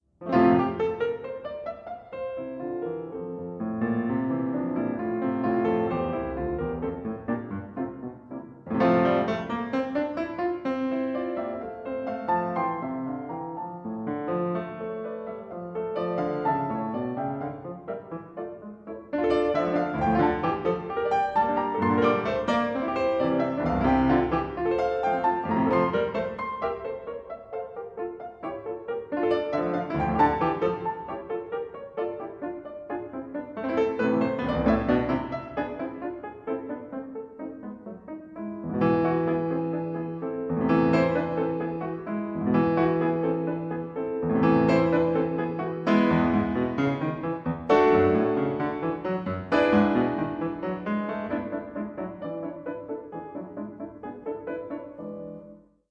Stereo
piano
Recorded 22-24 July 2013 at Potton Hall, Suffolk, England